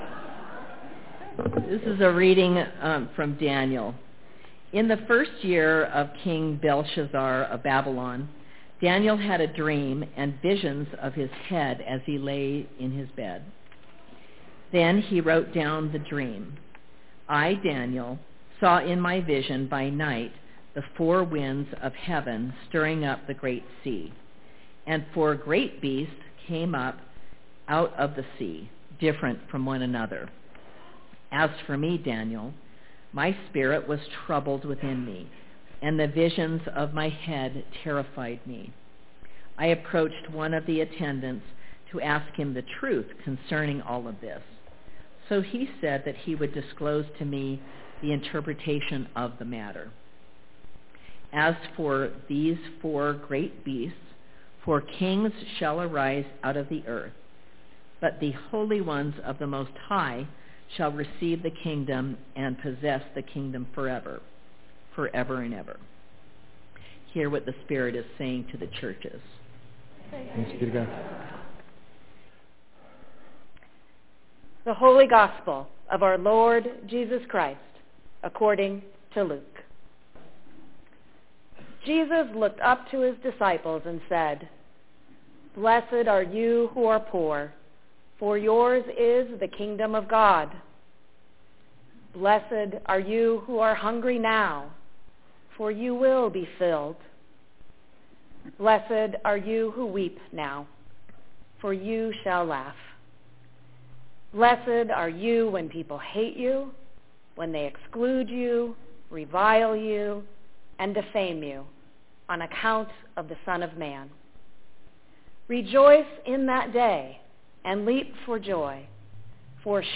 Prayer as connection and community is our topic today. Our scriptures on All Saints are Daniel 7:1-3,15-18 and Luke 6:20-31 are linked at the references and included in the recording.